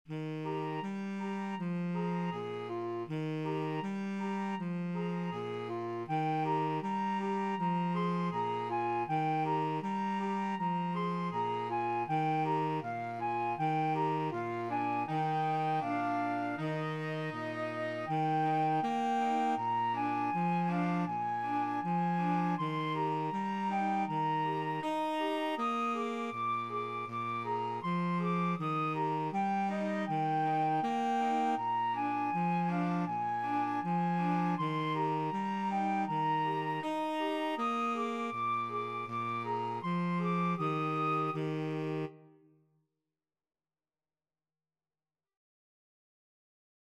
Flute
Tenor Saxophone
This traditional up-beat Passover song is over one thousand years old.
Eb major (Sounding Pitch) (View more Eb major Music for Flexible Mixed Ensemble - 3 Players )
4/4 (View more 4/4 Music)
Andante